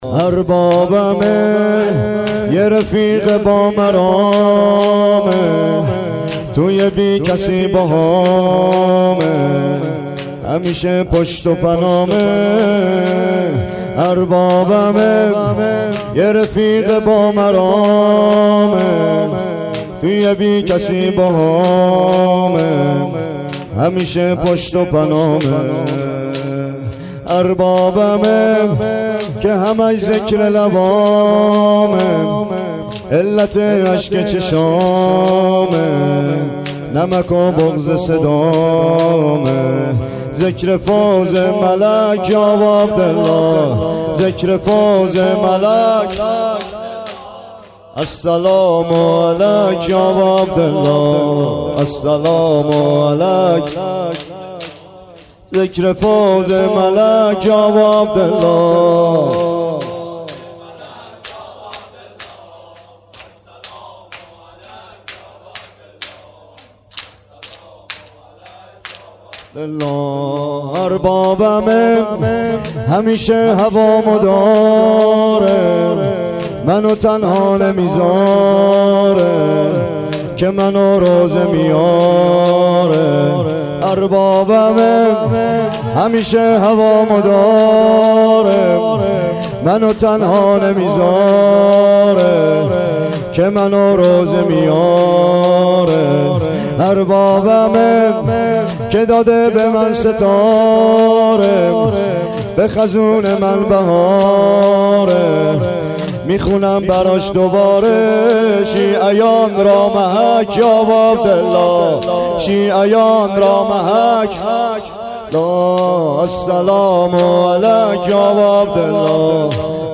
اربابمه ، یه رفیق با مرامه (سینه زنی/ سه ضرب